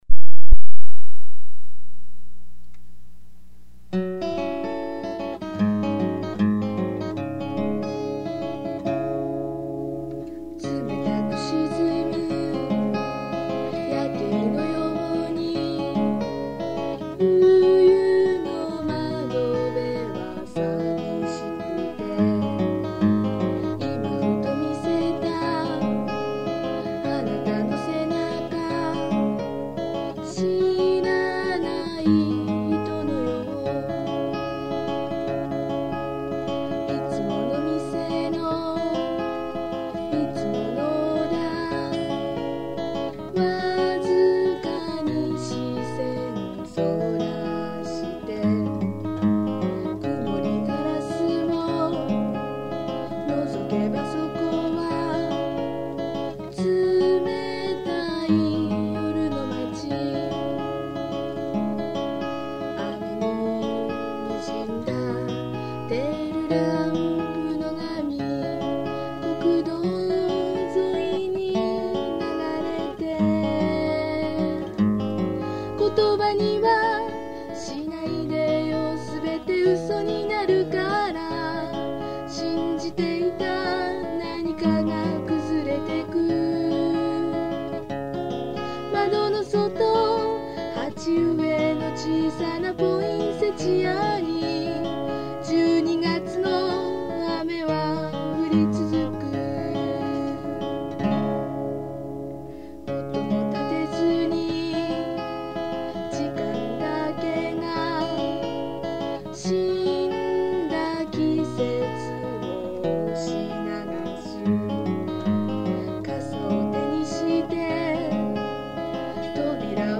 （Original　Key : c minor, by acoustic guitar）